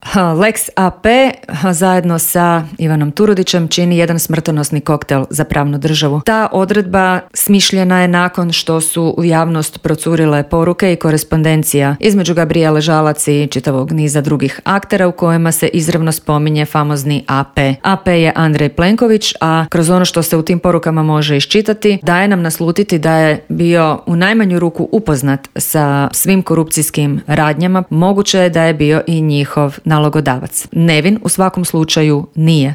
ZAGREB - Prijepori oko Ivana Turudića i procedure izbora glavnog državnog odvjetnika, izmjene Kaznenog zakona, veliki prosvjed oporbe, sindikalni prosvjedi i potencijalni štrajkovi neke su od tema o kojima smo u Intervjuu Media servisa razgovarali sa saborskom zastupnicom iz Stranke s imenom i prezimenom Dalijom Orešković.